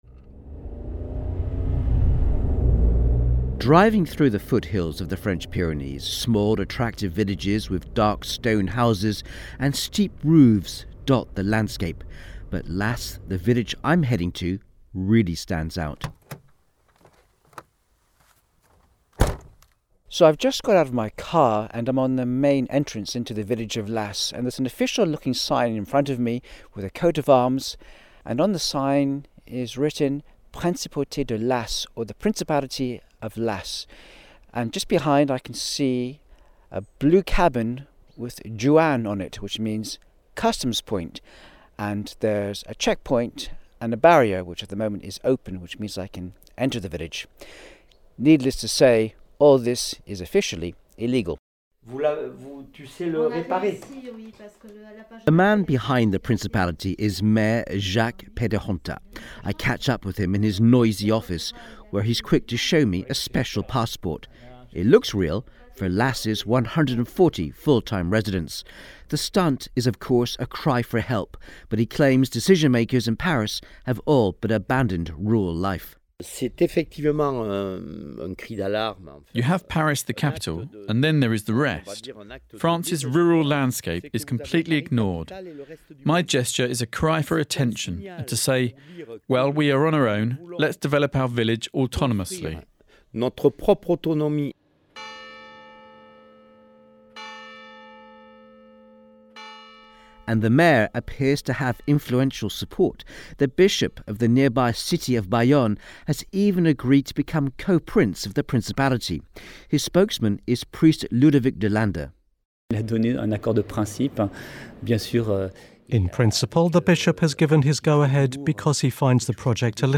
BBC radio broadcast about Laàs
The BBC radio make a report last november. In this village, the mayor Jacques Pédehontaà has set up a principality.